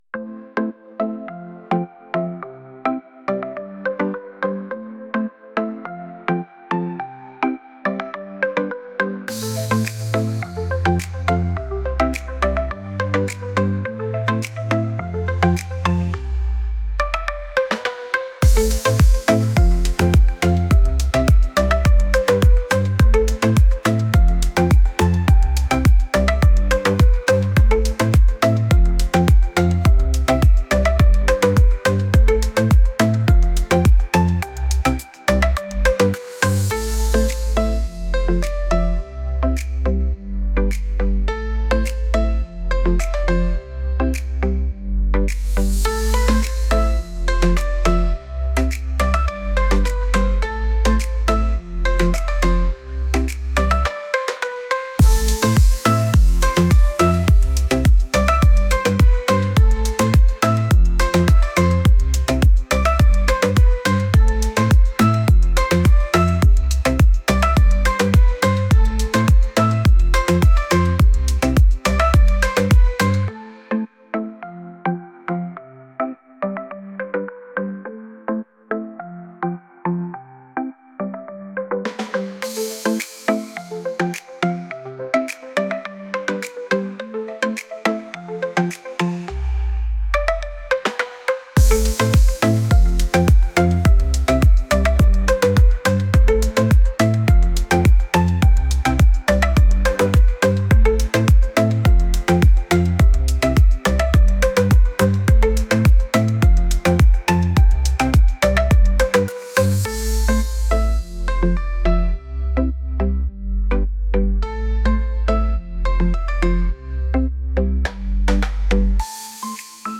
romantic | pop